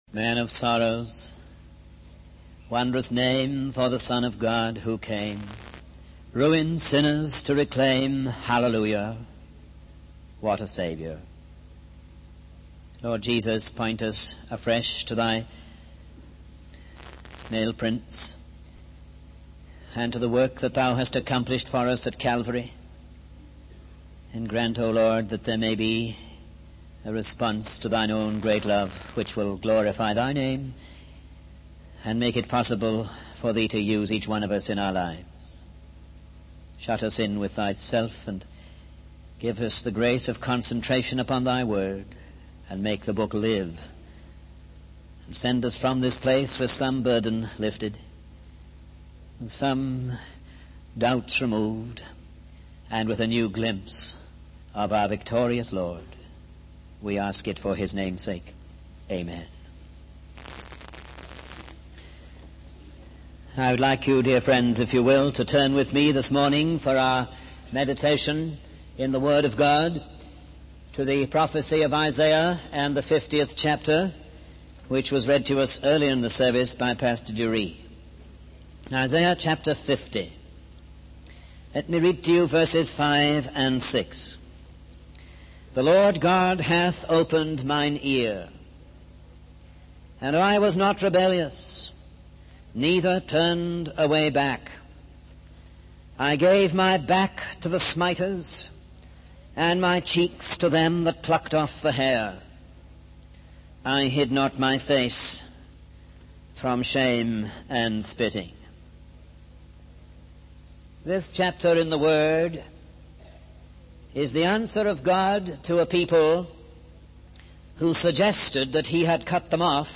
In this sermon, the speaker addresses the weariness and heartache that is prevalent in the world today. He emphasizes that God sent His Son to speak a word of comfort and encouragement to those who are weary in the battle.